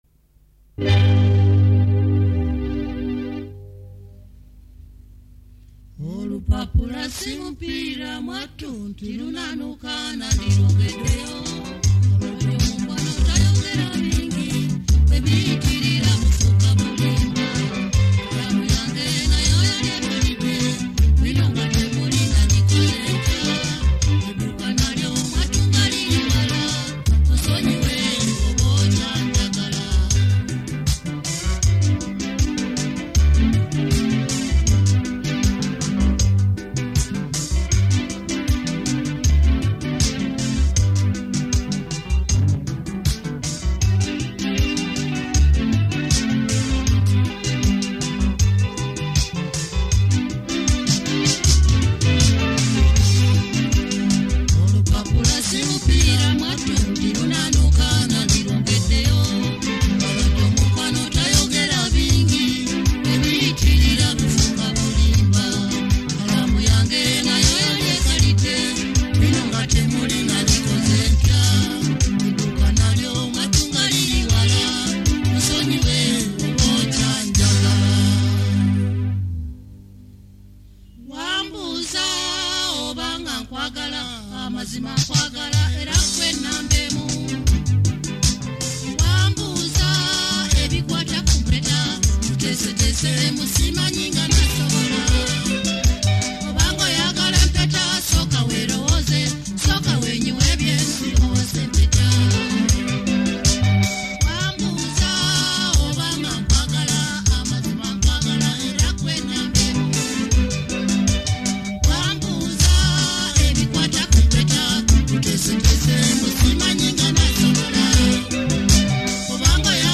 Kadongo Kamu